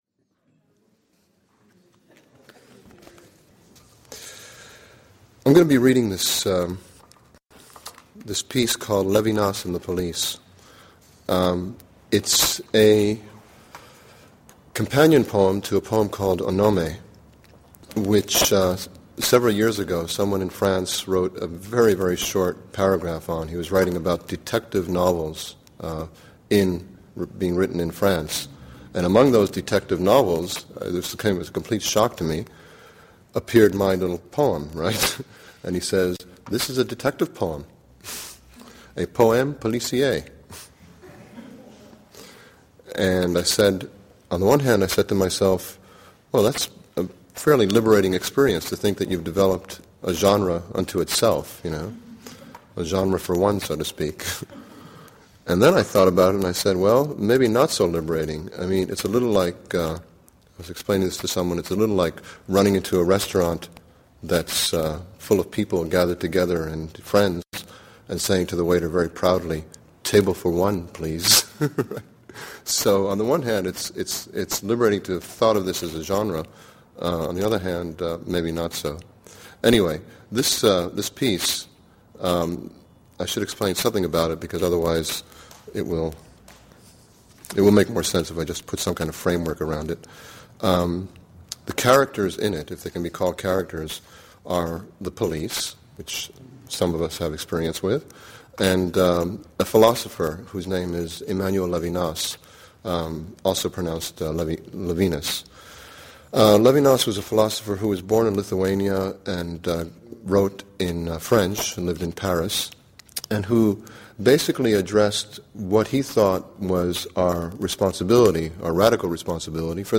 Lecture
enregistrement à l'Unitarian Church, San Francisco, 20/09/2001